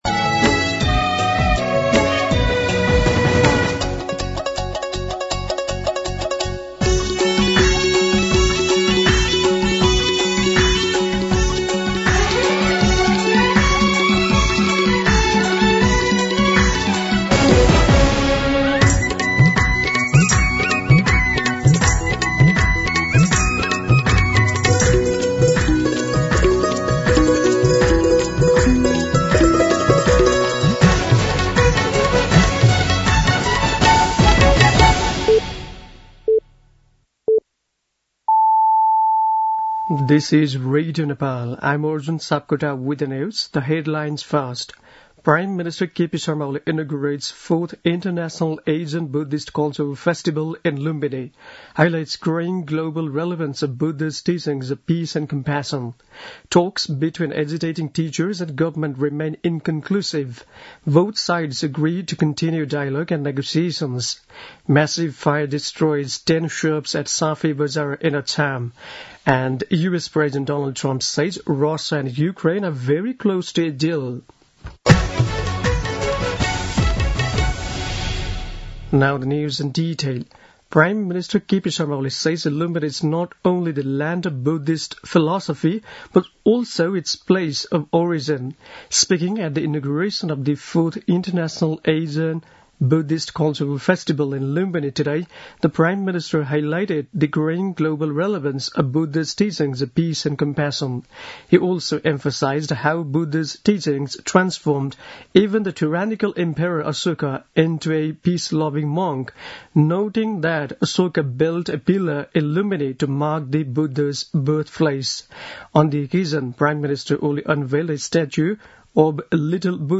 दिउँसो २ बजेको अङ्ग्रेजी समाचार : १३ वैशाख , २०८२
2pm-English-News-01-13.mp3